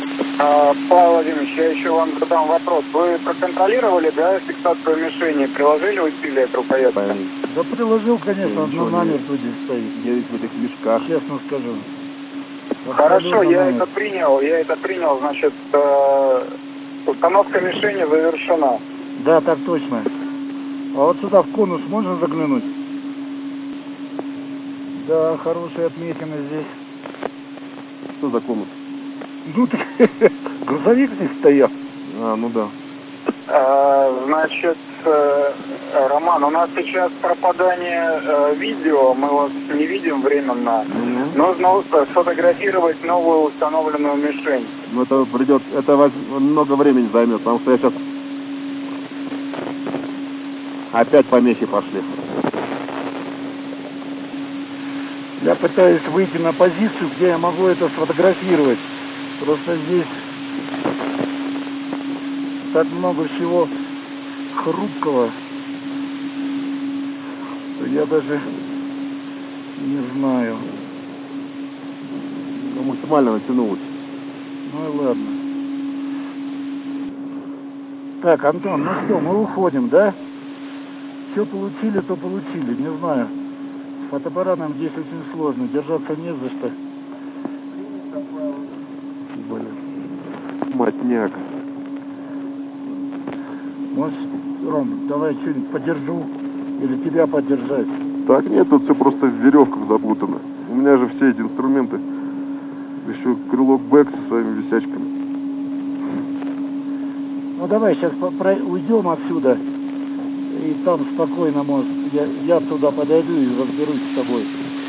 Выход в открытый космос